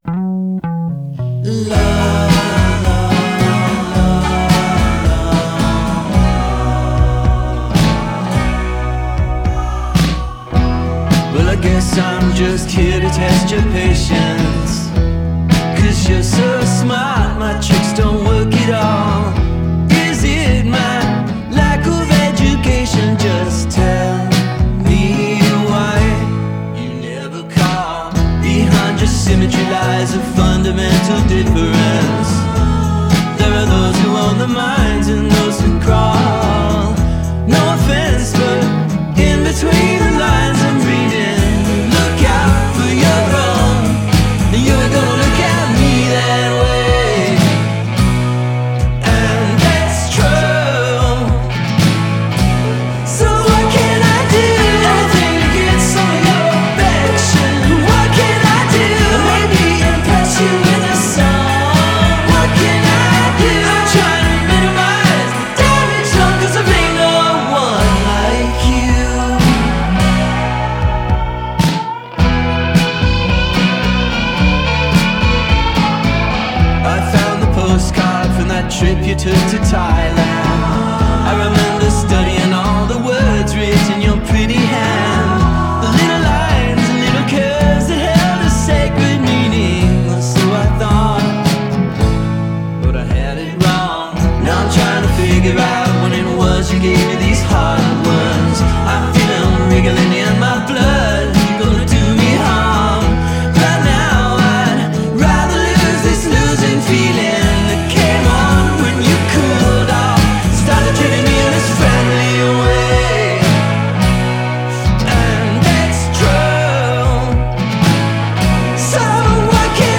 layers up the poprock sonic landscape